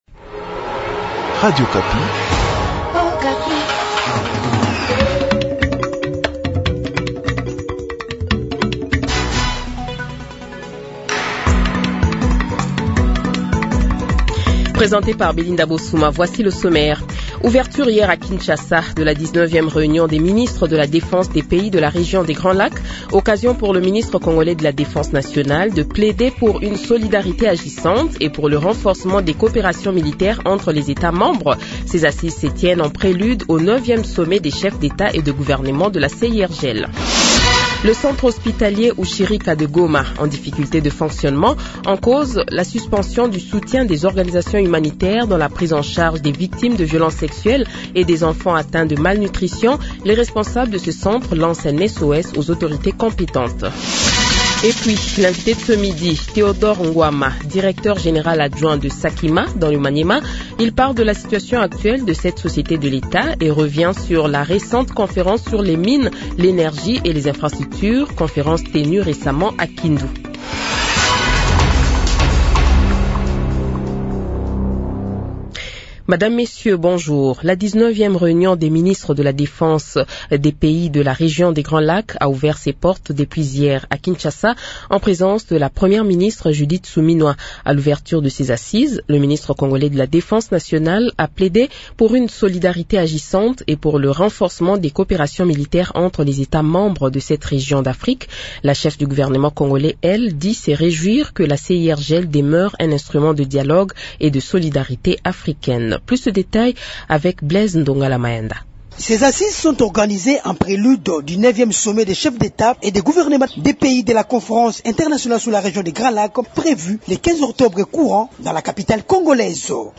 Journal Francais Midi
Le Journal de 12h, 09 Novembre 2025 :